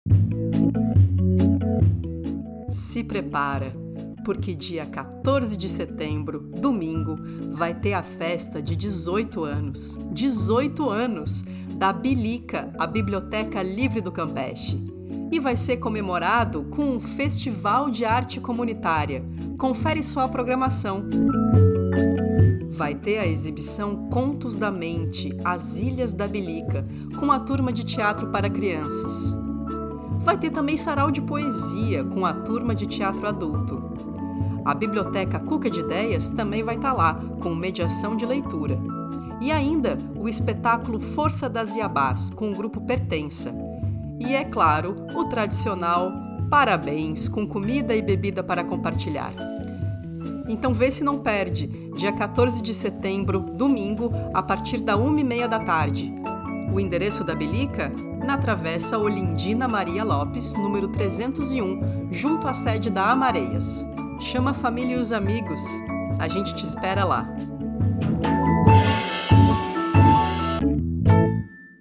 vinheta-aniver-bilica-2025.mp3